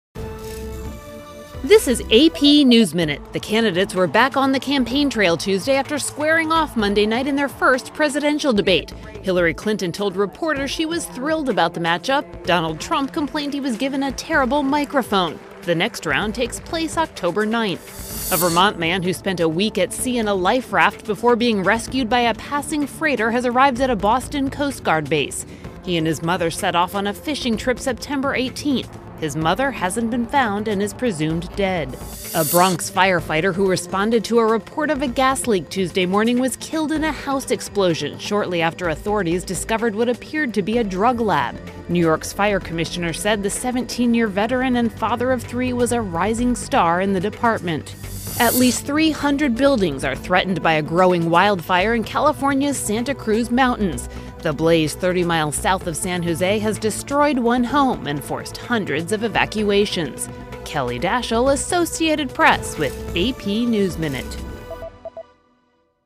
美语听力练习素材:美国总统选举进行首场辩论|美语听力练习素材
News